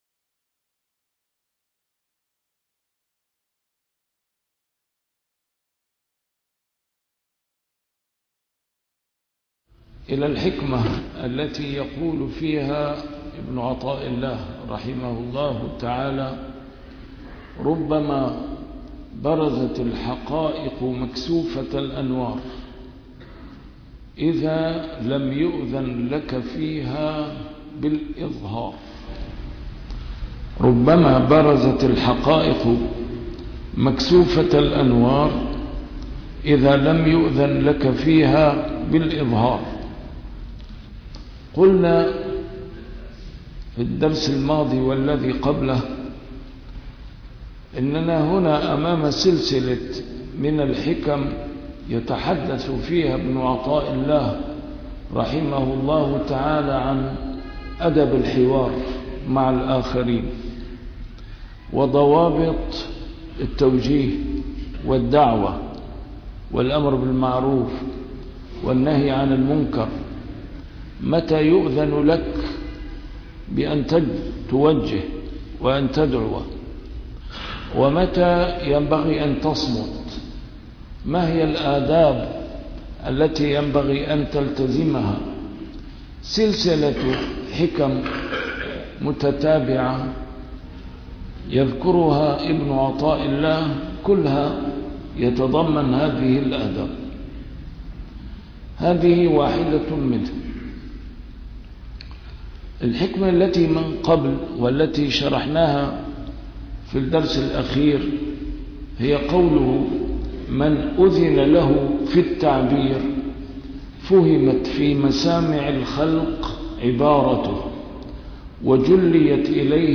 A MARTYR SCHOLAR: IMAM MUHAMMAD SAEED RAMADAN AL-BOUTI - الدروس العلمية - شرح الحكم العطائية - الدرس رقم 205 شرح الحكمة رقم 184